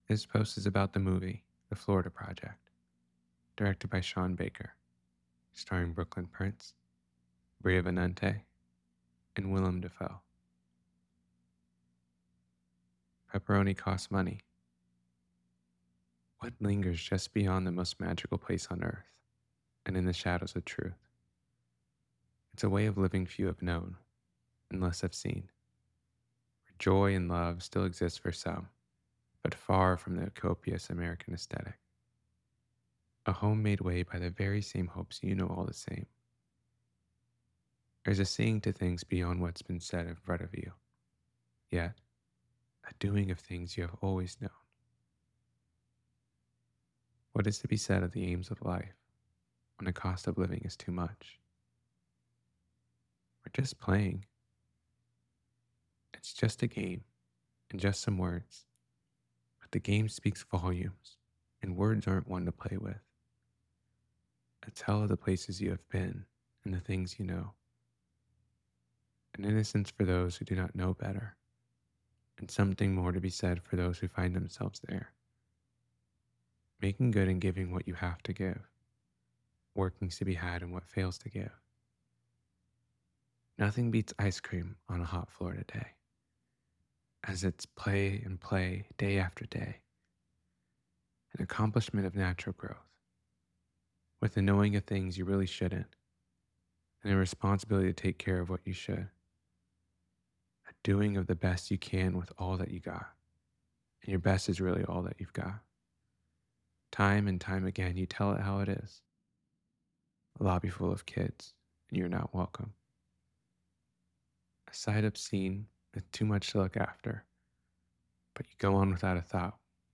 the-florida-project-to-know-a-story-reading.mp3